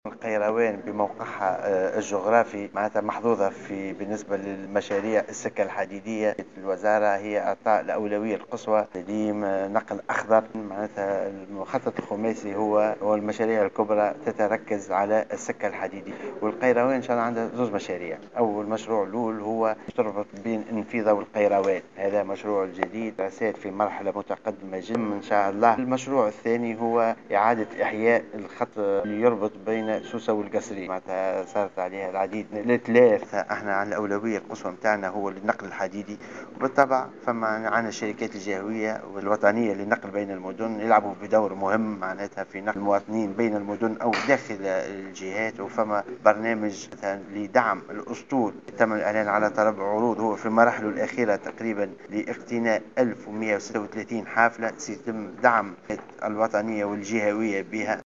وأفاد في تصريح لمراسل "الجوهرة أف أم" أن القيروان تحظى باهتمام كبير على مستوى النقل بالمخطط الخماسي الذي سيرتكز على السكة الحديدية.
وجاءت تصريحات وزير النقل، انيس غديرة، على هامش زيارة ميدانية اداها اليوم الى عدد من المؤسسات التابعة للشركة الجهوية للنقل بالقيروان، اكد خلالها أيضا أنه سيتم دعم اسطول الحافلات للنقل بين المدن من خلال اقتناء 1136 حافلة لدعم الخطوط الوطنية والجهوية، بحسب تعبيره.